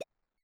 New grunk collection SFX